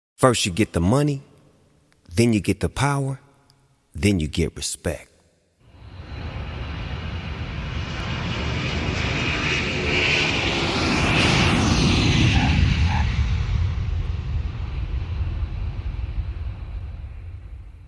classic West Coast sound with smooth beats and cool lyrics
deep voice and relaxed flow
Fans of old-school hip-hop will love this album.